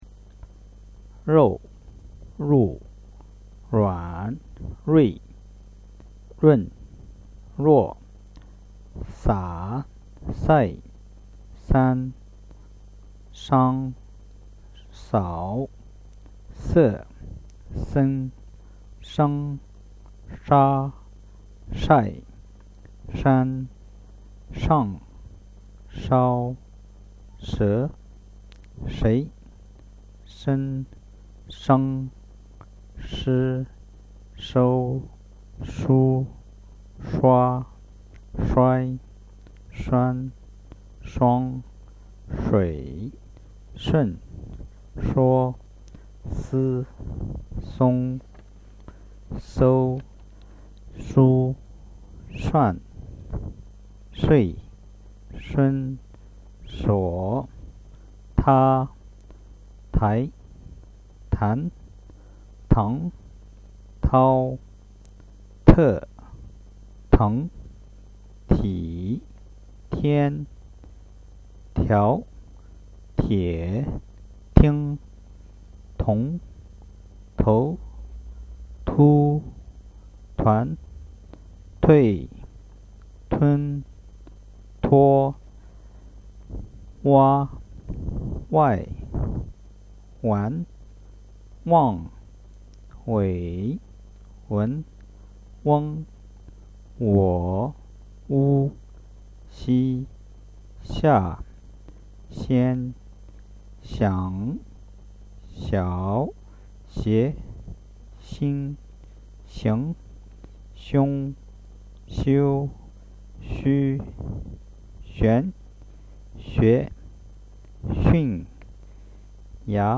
The sounds and description list of the Chinese basic syllables